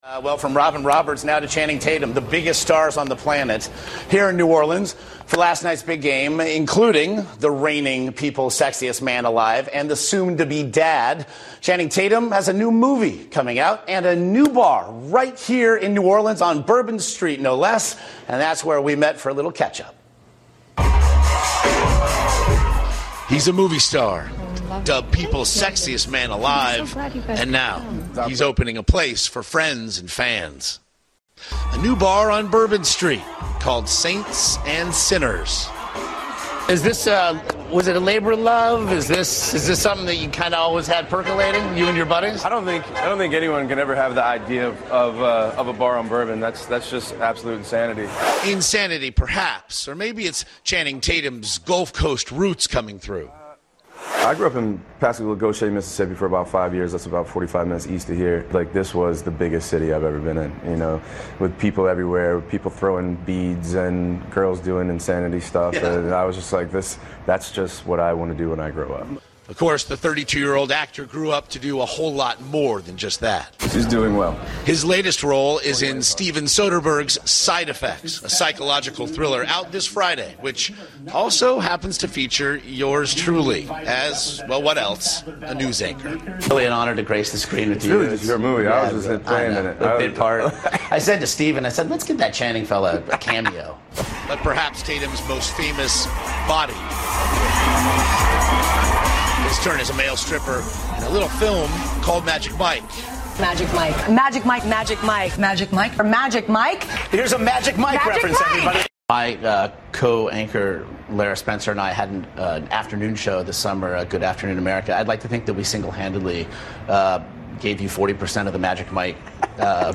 访谈录 2013-02-19&02-21 年度最性感男星钱宁专访 听力文件下载—在线英语听力室